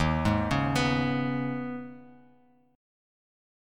D#7sus4#5 chord